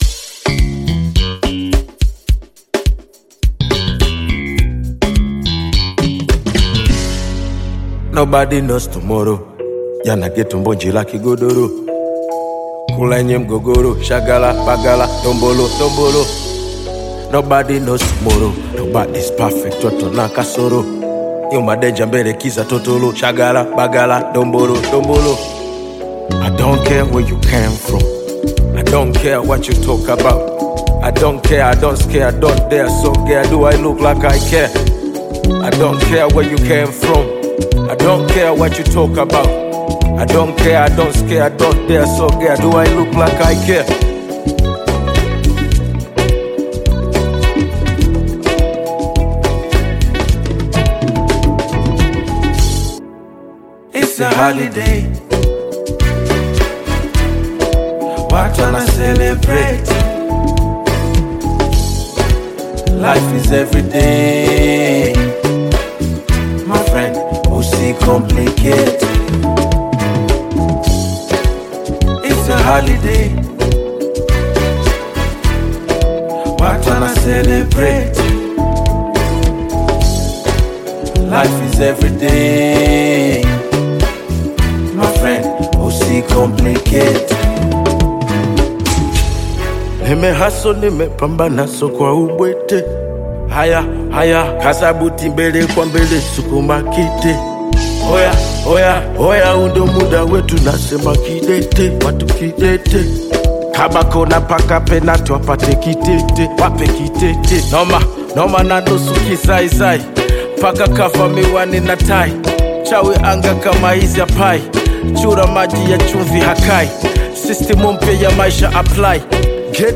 Sensational Tanzanian singer and composer
is an energetic song